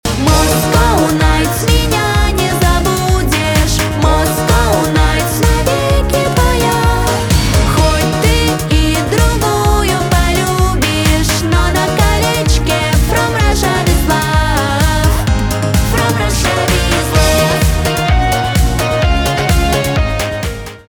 поп
диско
красивый женский голос